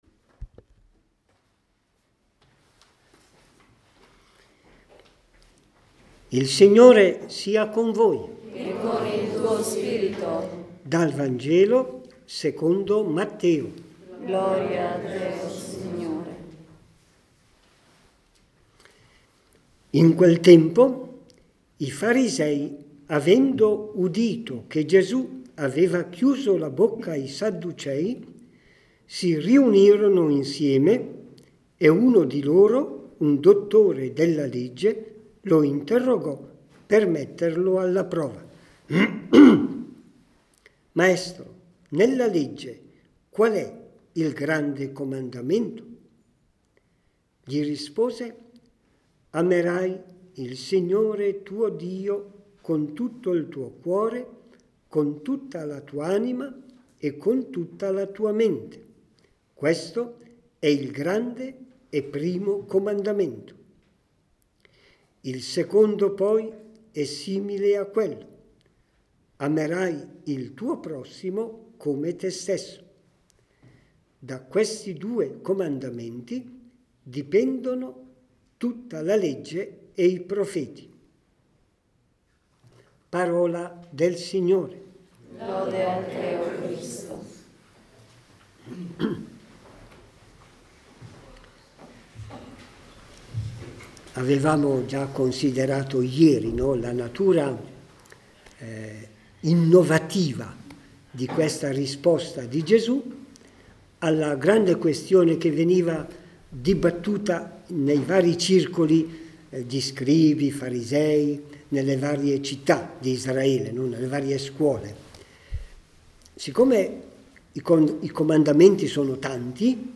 Esercizi per laici